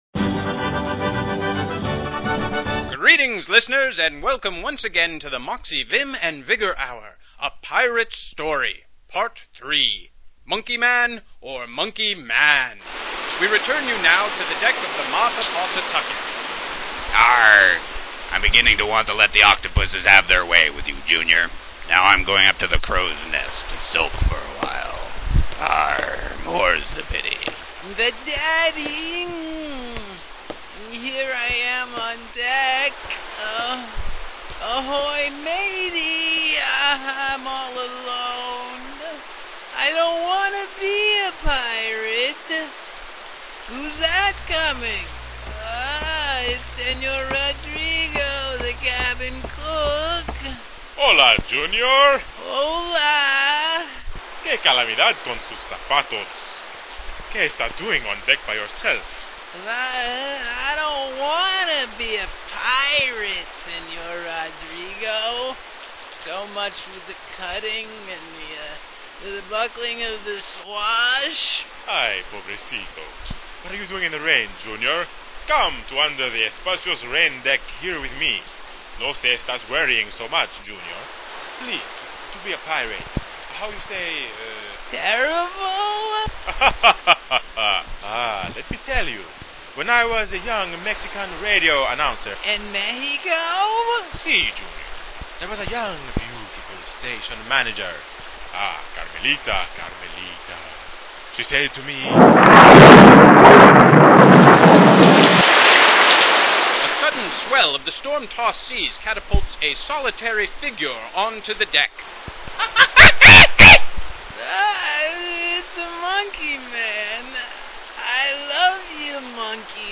If a) you would rather not bother with the plugin thingy - or - b) have some difficulty playing the radio play, a downloadable mp3 file is available here (1.64meg, but worth every second)